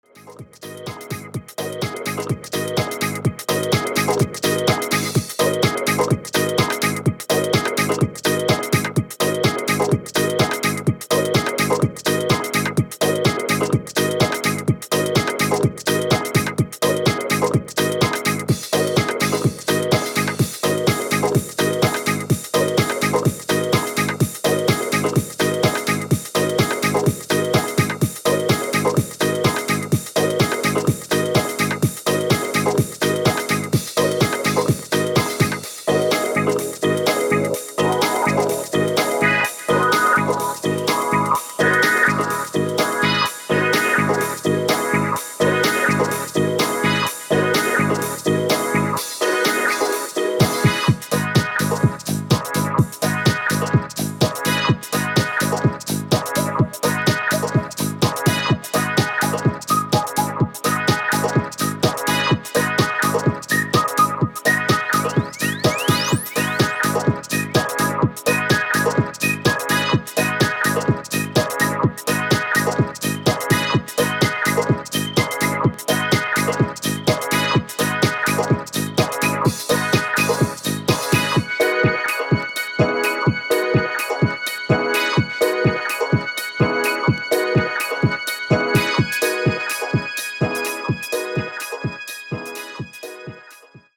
時間軸関係ない長く使えるDeep House 4曲収録12"です。